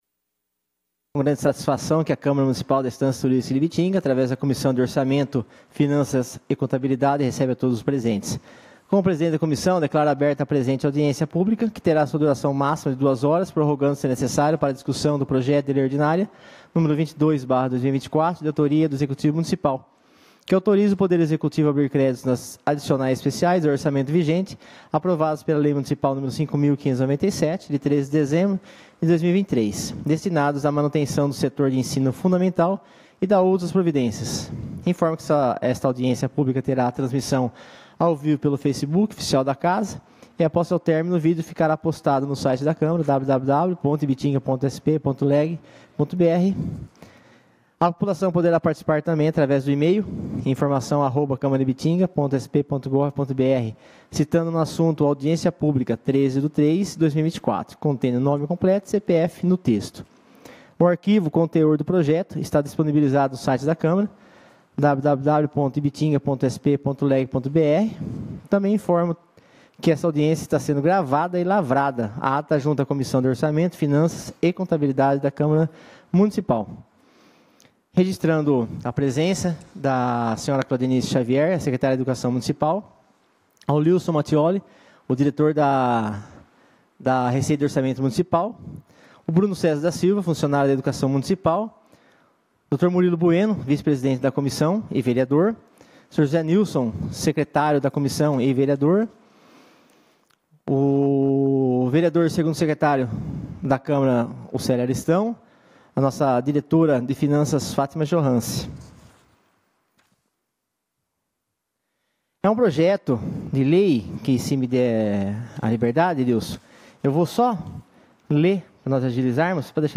Audiência